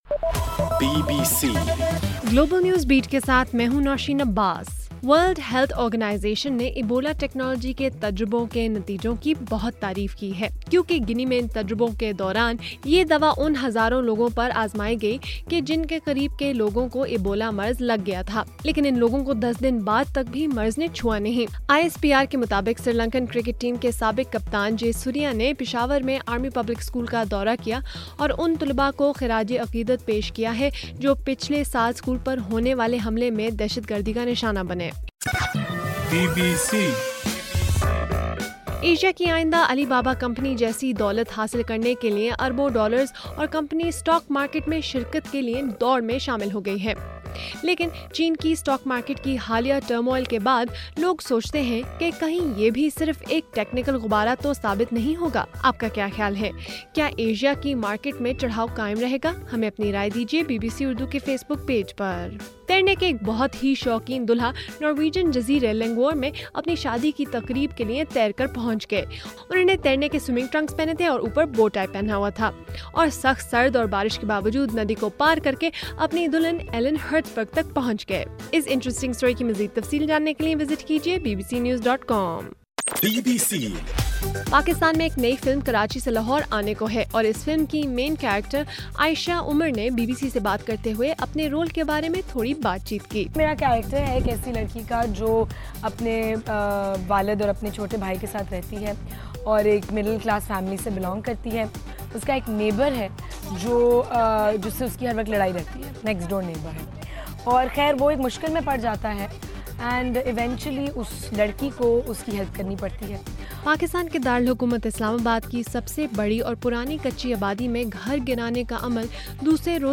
جولائی 31: رات 12 بجے کا گلوبل نیوز بیٹ بُلیٹن